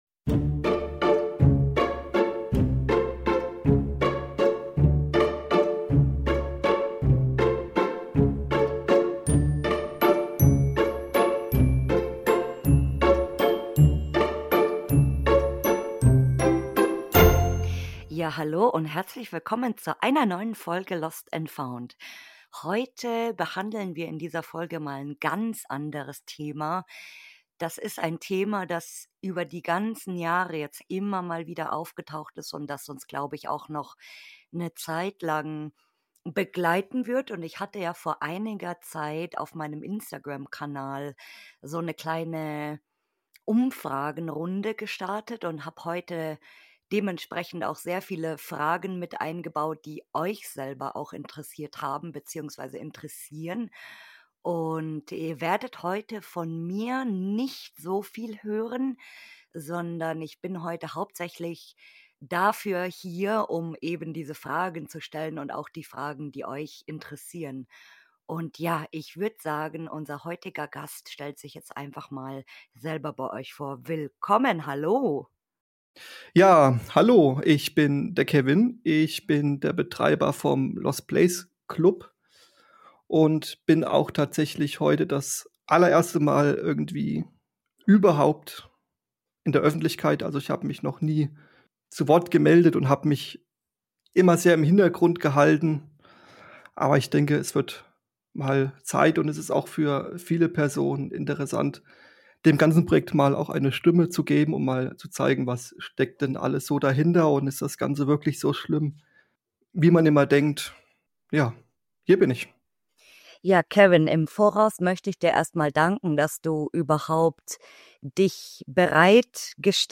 Ich hatte die Gelegenheit, mit jemandem zu sprechen, der eine Plattform für Lost-Places-Karten betreibt. Was sind die Beweggründe hinter dem Projekt? Wie funktioniert das Ganze im Hintergrund? Und wer ist eigentlich die Person hinter der Plattform?